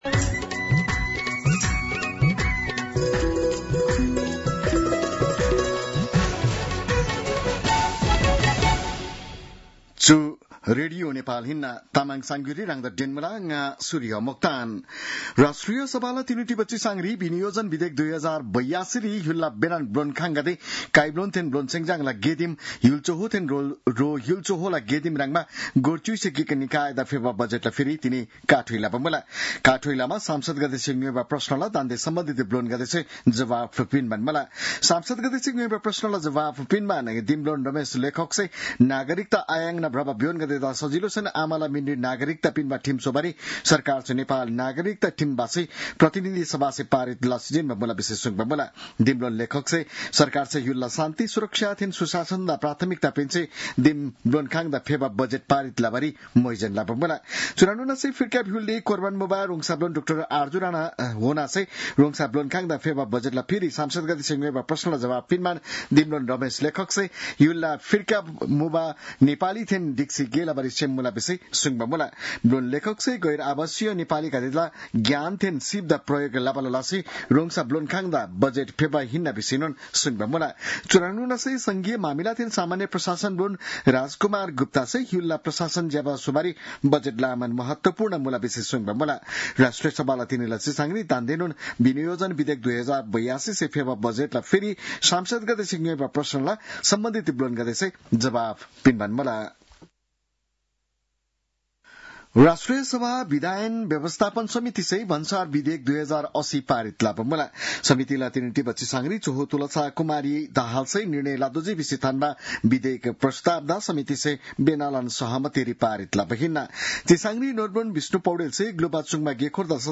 An online outlet of Nepal's national radio broadcaster
तामाङ भाषाको समाचार : १७ असार , २०८२